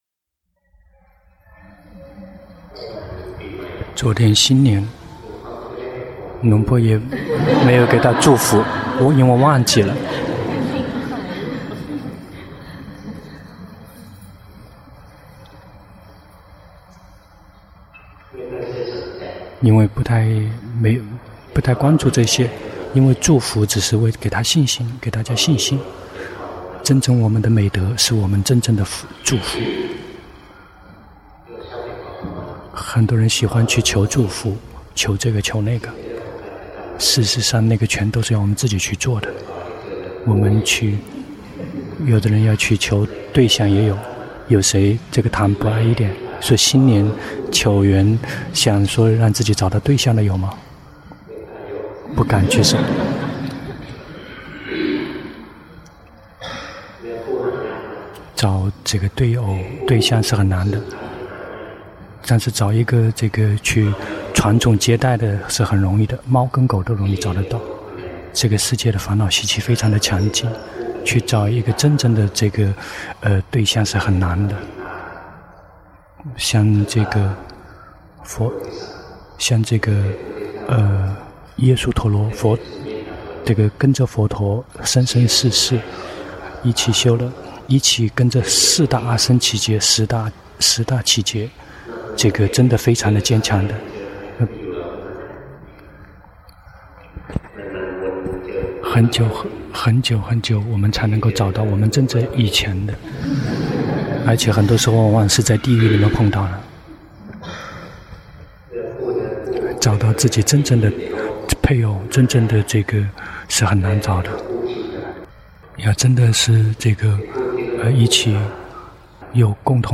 長篇法談｜伴侶和歸依處——隆波帕默尊者 - 靜慮林